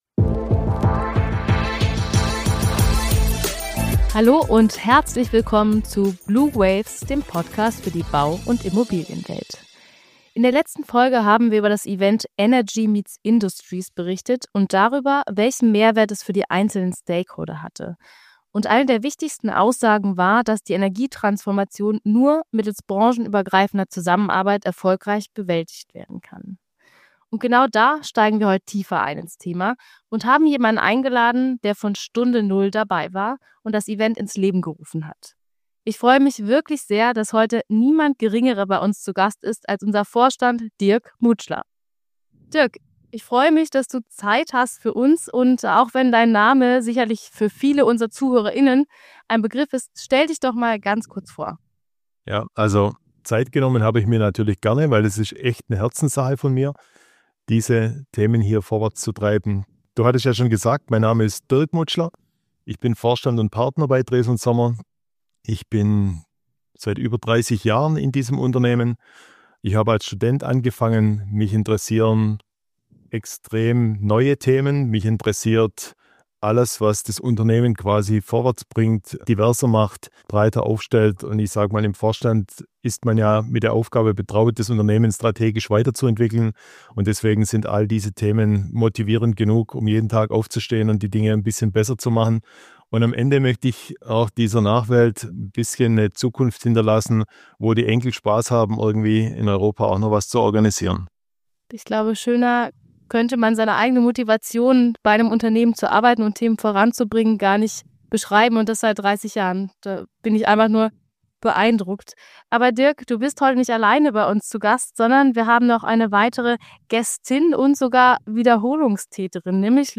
Lauschen Sie den fachlich-persönlich geführten Gesprächen mit verschiedenen Expertinnen und Experten.